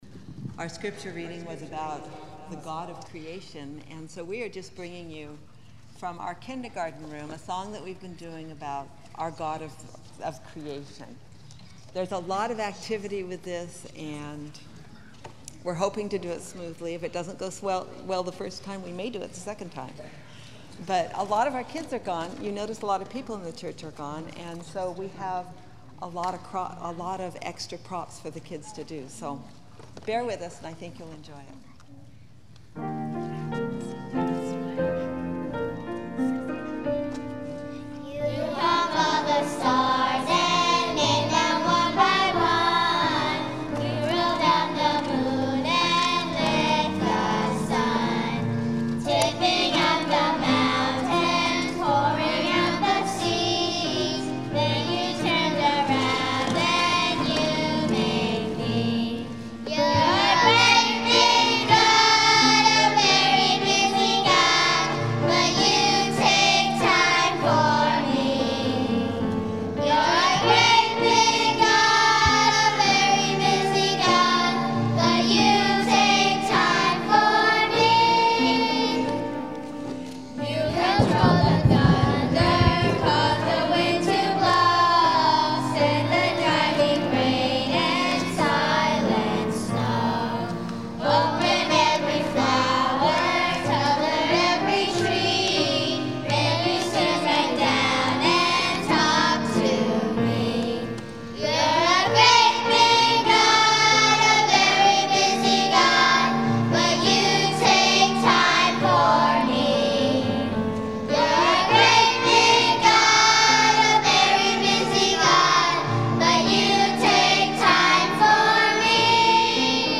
Speacial Children Music